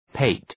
{pɑ:’teı}